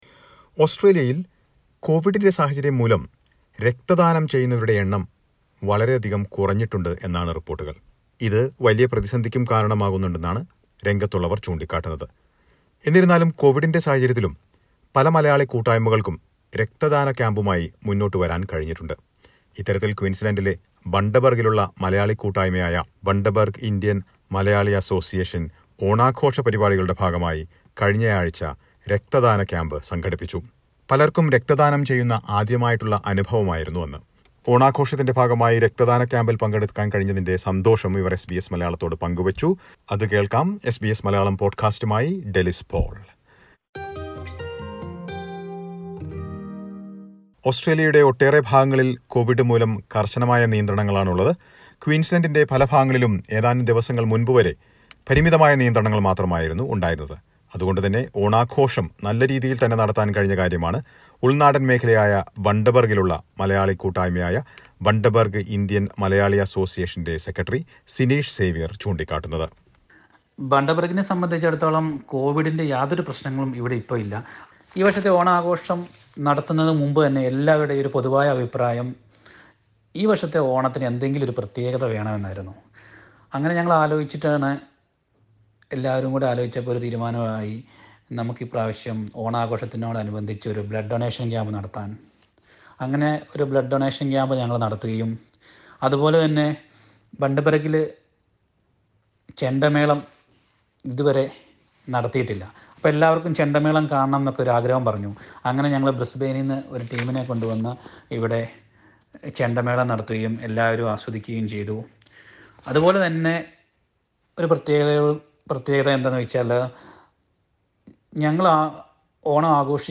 Listen to report on Blood donation camp and Onam celebration by Bundaberg Malayalees.